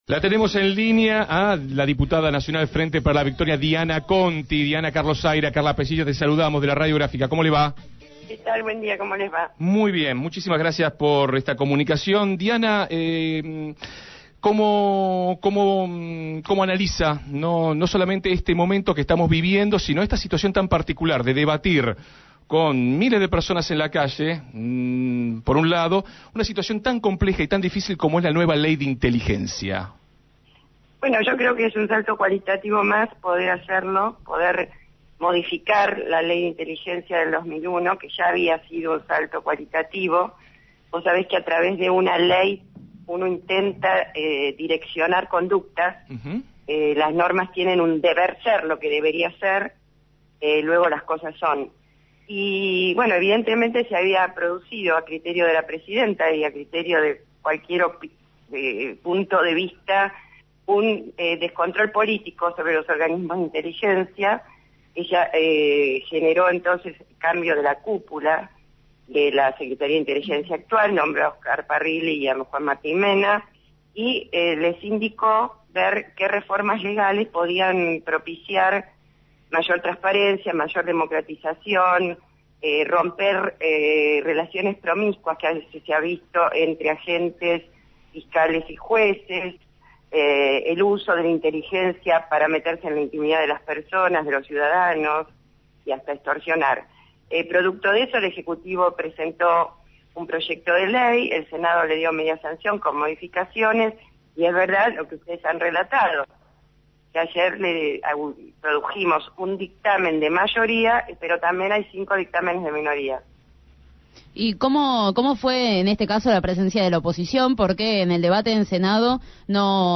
La diputada nacional por el Frente Para la Victoria, Diana Conti, fue entrevistada en Desde el Barrio. En el aire de Radio Gráfica, hizo mención al proyecto de creación de la Agencia Federal de Inteligencia y también a la denuncia que ahora está en manos del fiscal Gerardo Pollicita.